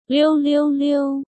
liù liù liù